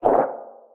Sfx_creature_penguin_hop_voice_04.ogg